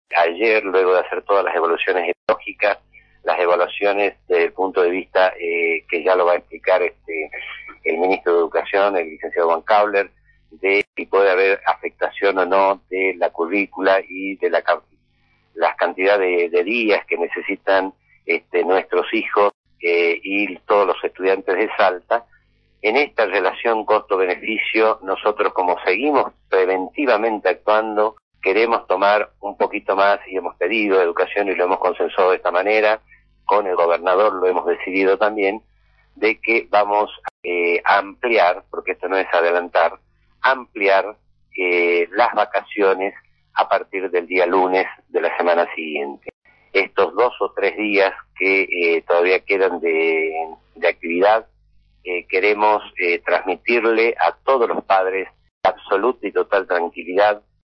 Audio ministros de Salud, Alfredo Qüerio
Para el Ministro de Educación de esta provincia “Esta situación es dinámica, cambia constantemente, por eso tenemos que tener en cuenta el escenario nacional y el salteño por la condición de destino turístico…) Afirmo el ministro de Educación en conferencia de prensa.